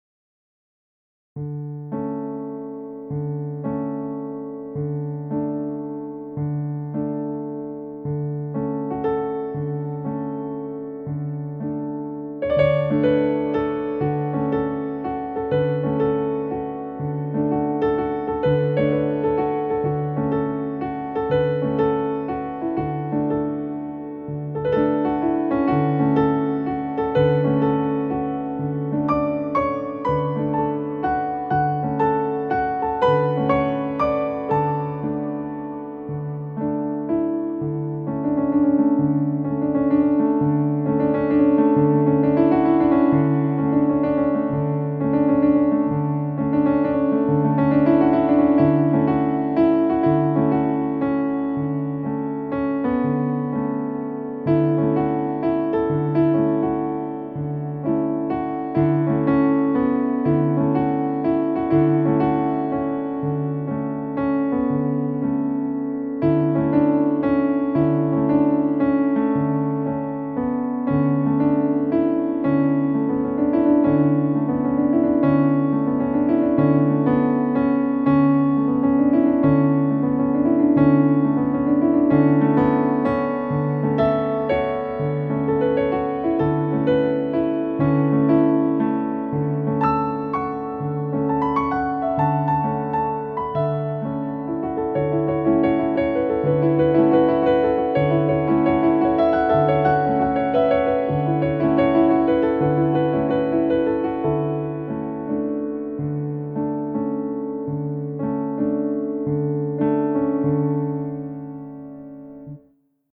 Occasionally I write piano music.